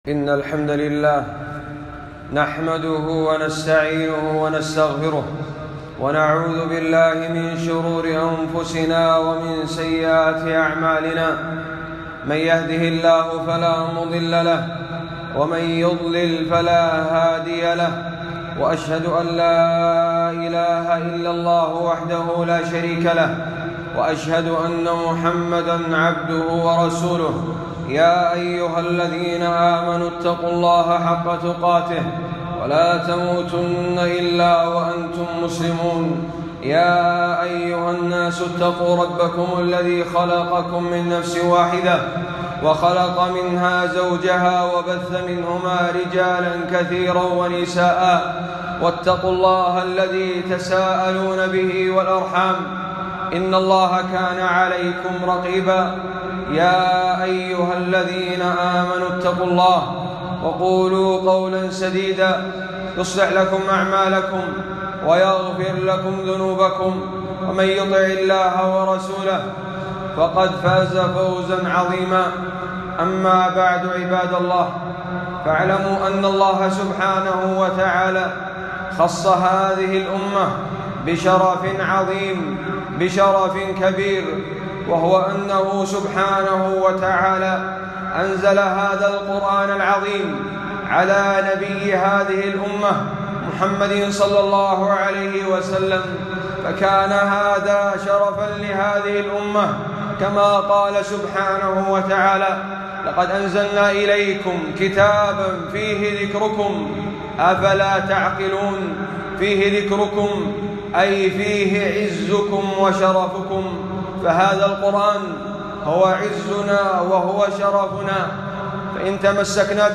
خطبة - الترغيب والترهيب في القرآن